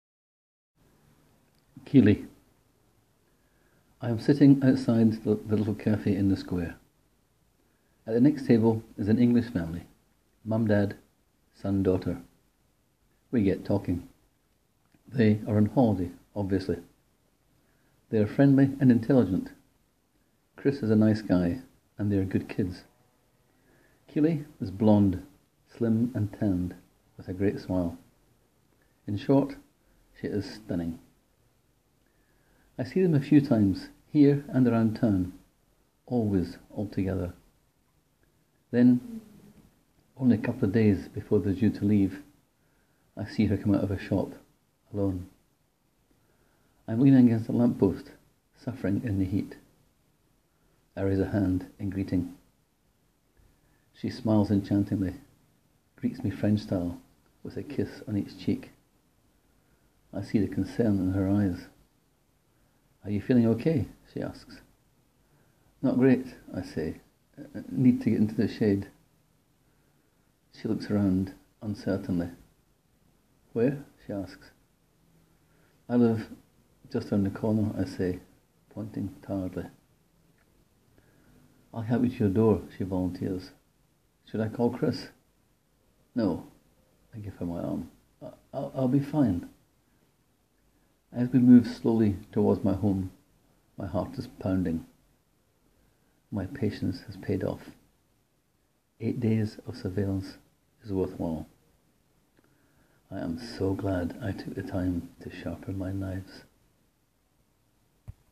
Click here to hear me read the story: